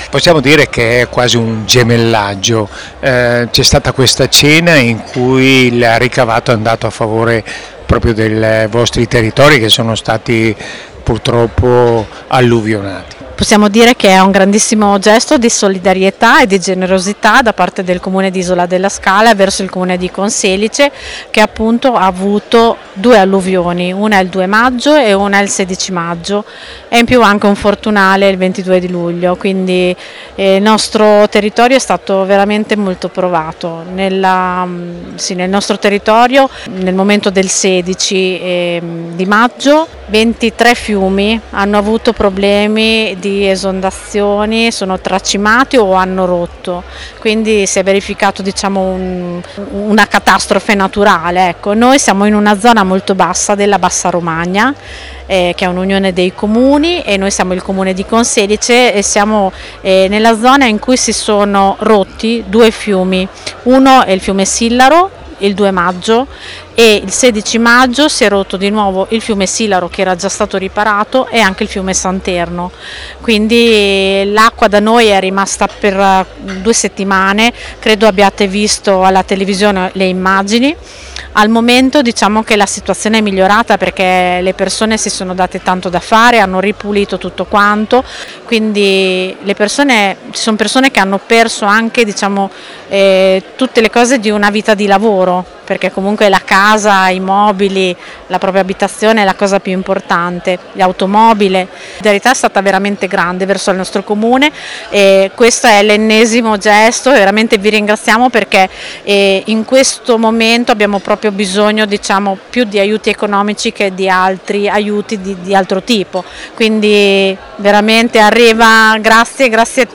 all’evento inaugurale:
Raffaella Gasparri Assessore al Sociale del Comune di Conselice